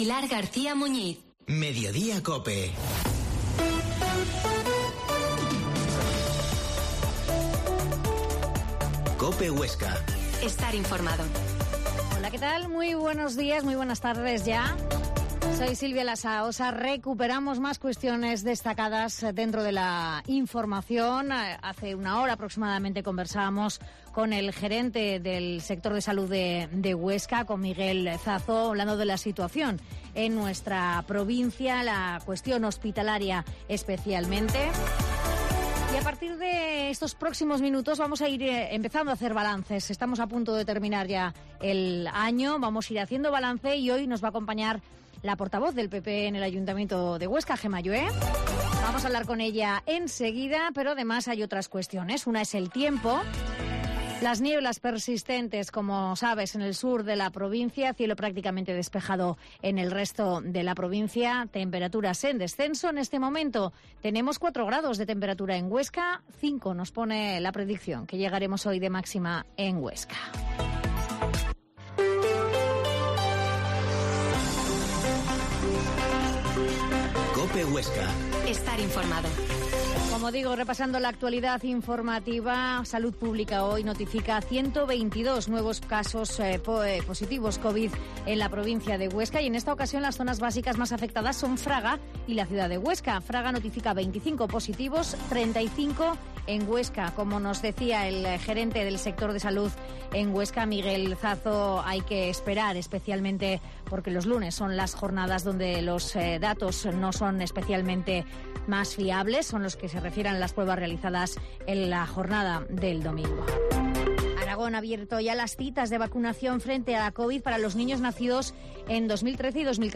Mediodia en COPE Huesca 13.20h Entrevista a la portavoz del PP en el Ayuntamiento de Huesca Gemma Allué